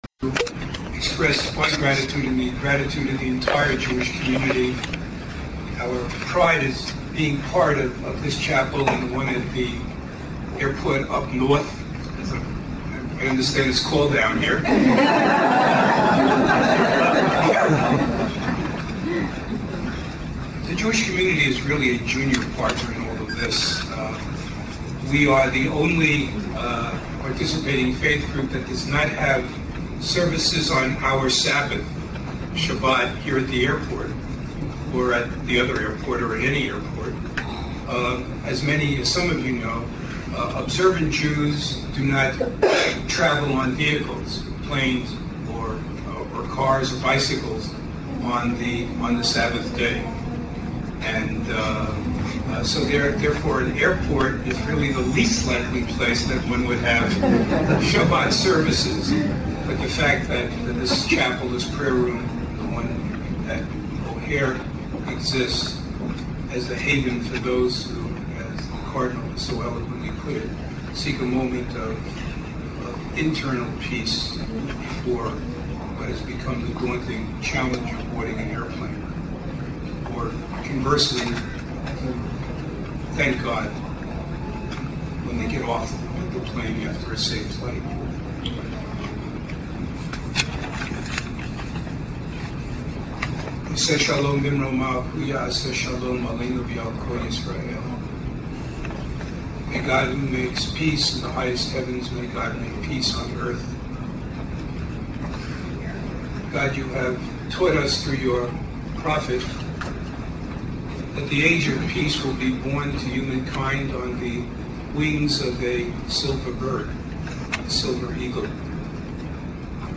Audio Clips: Click on each link to download the Homily by Francis Cardinal George, OMI and the Prayers of Blessing offered by various clergy in attendance at the April 14, 2002 Vespers Service Dedicating the Midway Chapel.
Prayer for Peace